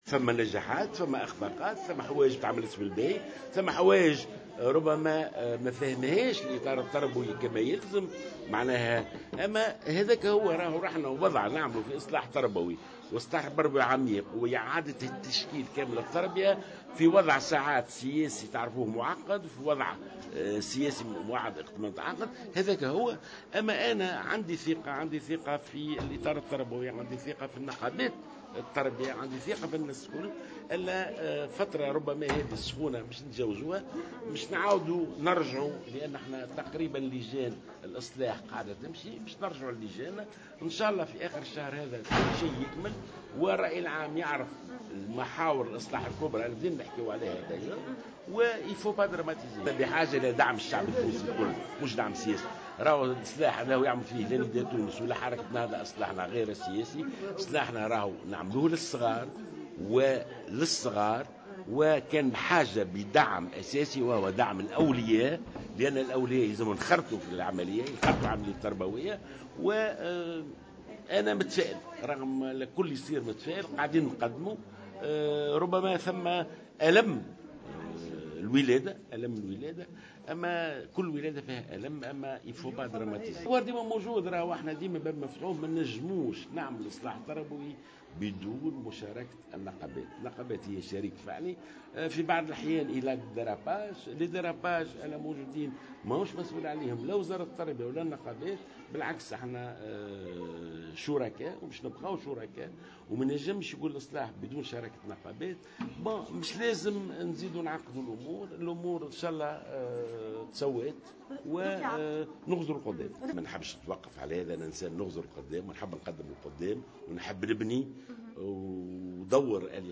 قال وزير التربية ناجي جلول في تعليقه على احتجاجات الأساتذة والمعلمين والمطالبة برحيله إن لديه ثقة في النقابات والإطار التربوي وإن هذه الفترة "الساخنة" سيتم تجازوها، كما ستتابع لجان الإصلاح عملها.